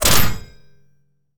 combat / weapons / mgun / metal1.wav
metal1.wav